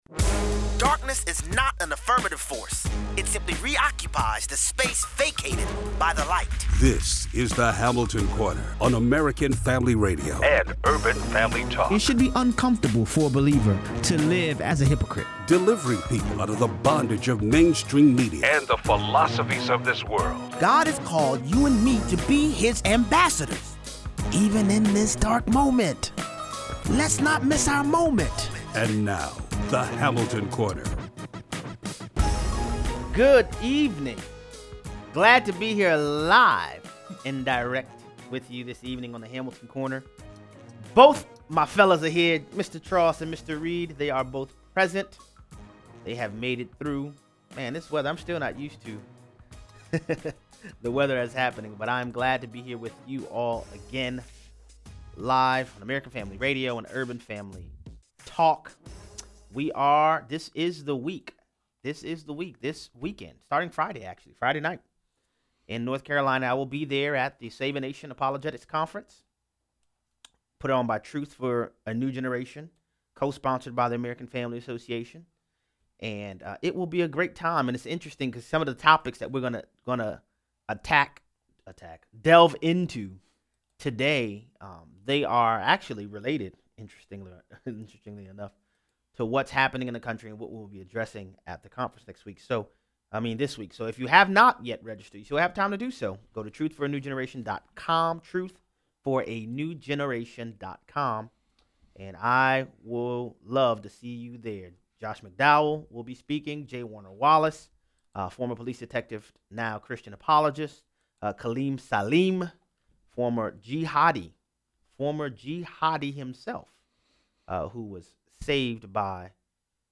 According to the Center of Disease Control suicide among teens have skyrocketed by 70% in the last 10 years. The world scrambles to explain the trend apart from Christ. 0:43 - 0:60: A fifth package explodes in Texas. Is a serial bomber on the loose? Callers weigh in.